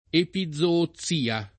epizoozia